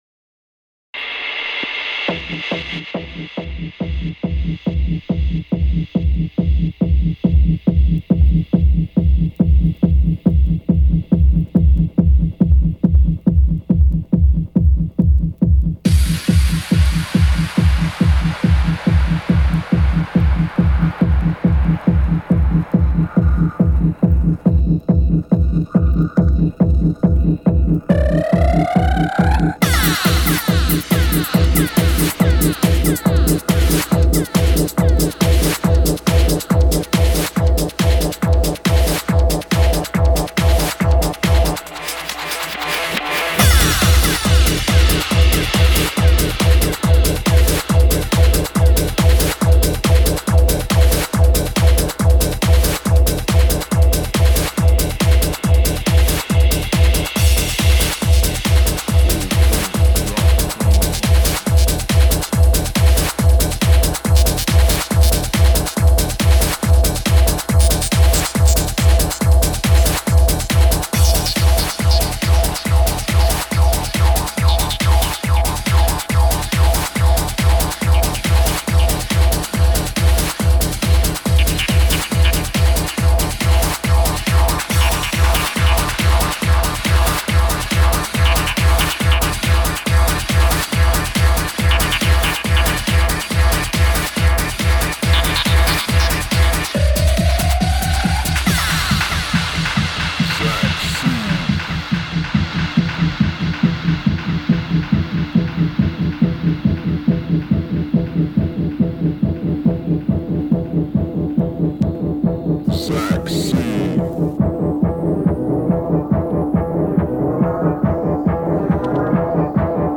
Two short mixes, one from each DJ.
Bangs all the way through!